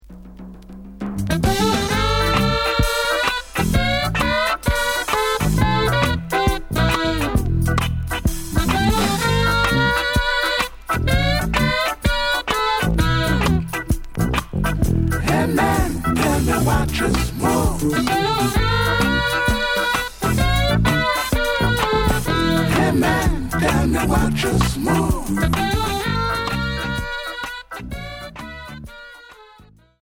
Reggae Unique 45t retour à l'accueil